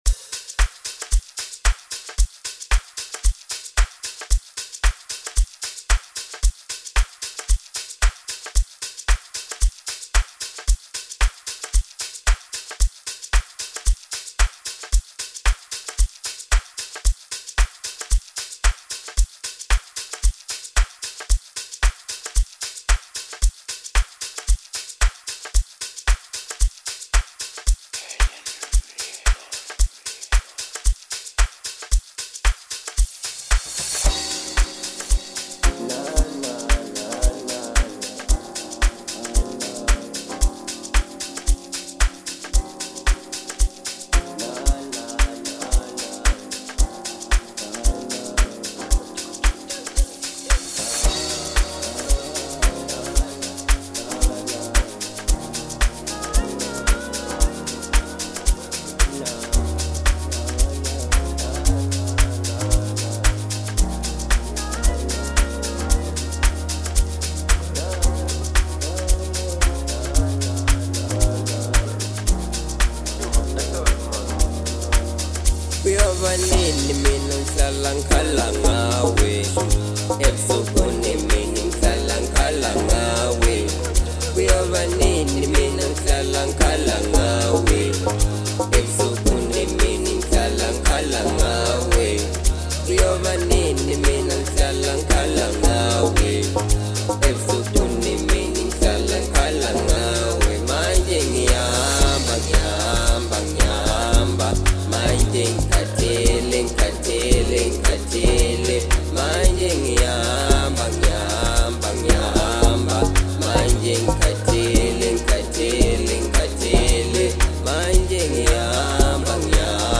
05:01 Genre : Amapiano Size